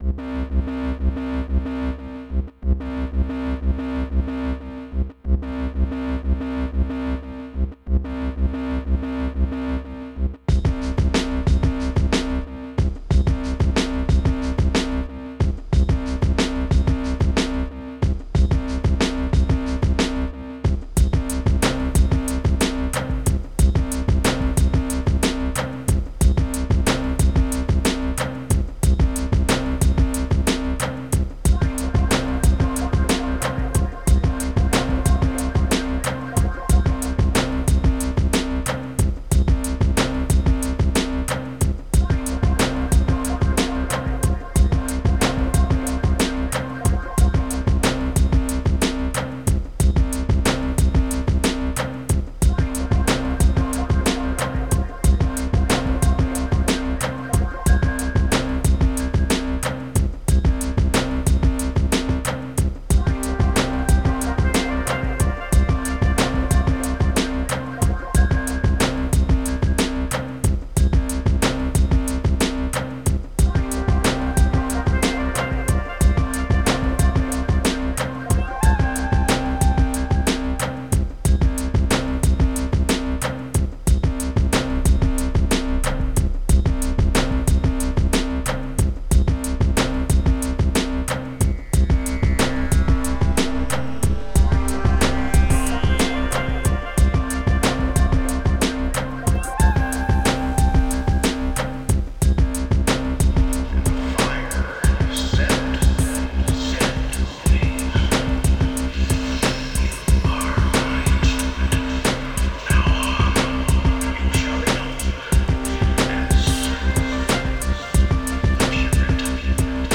wave (this so cool piano)
bass ....